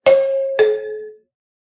announcement.ogg